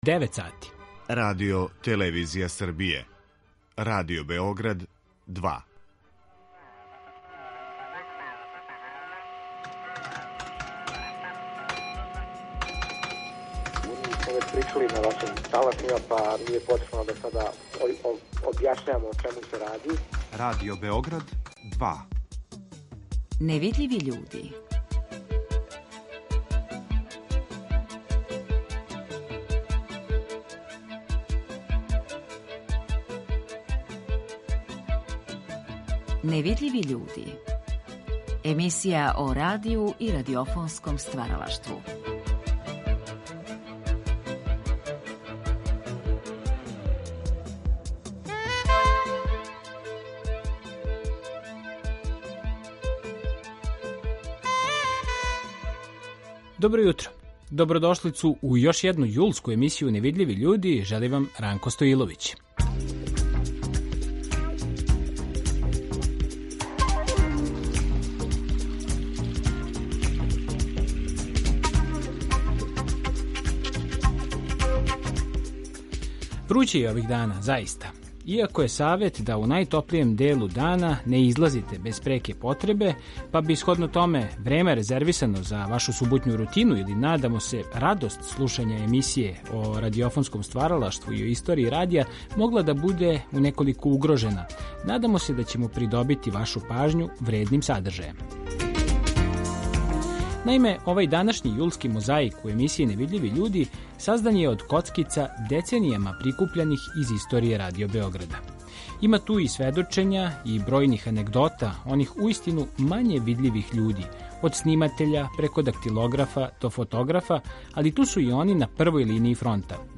Овај јулски мозаик у емисији Невидљиви људи саздан је од коцкица деценијама прикупљаних из историје Радио Београда. Има ту и сведочења и бројних анегдота оних уистину мање видљивих људи - од сниматеља преко дактилографа до фотографа, али ту су и новинари, спикери, па и гости предратног Радио Београда, слушаоци који су плаћали претплату за радио-станицу...